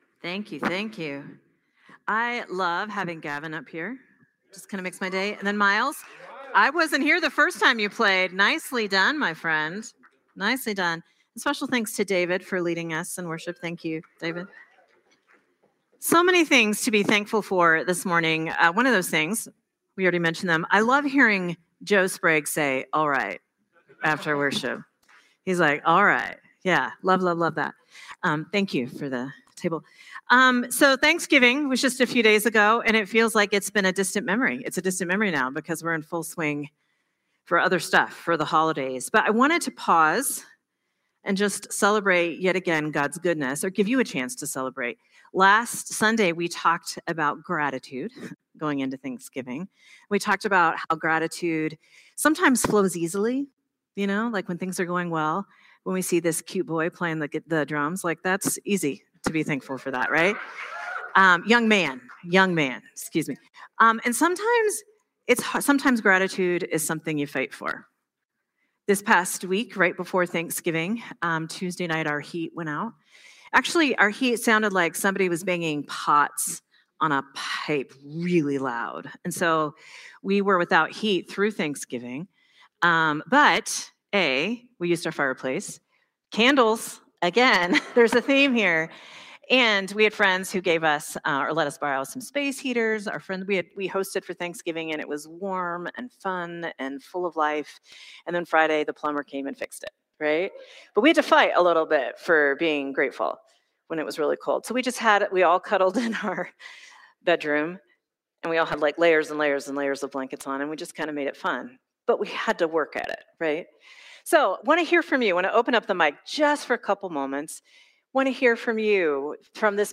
Sermon from Celebration Community Church on November 30, 2025